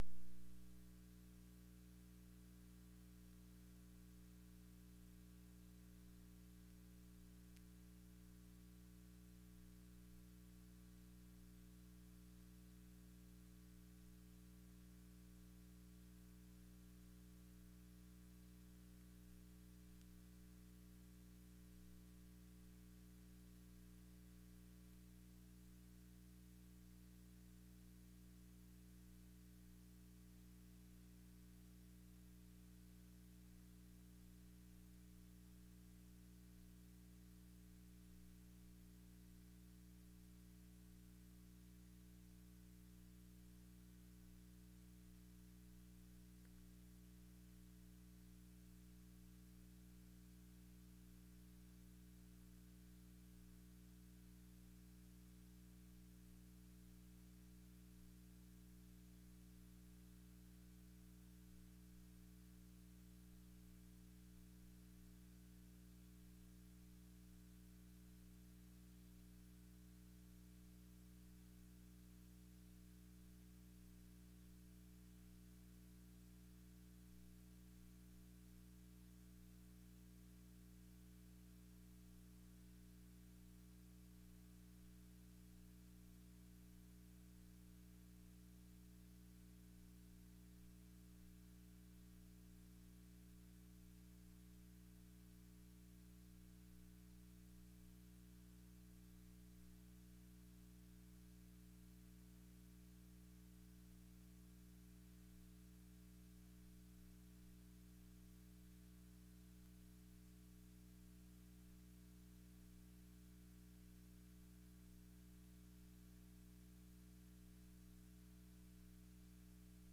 Sessão Ordinária do dia 29 de Novembro de 2021 - Sessão 43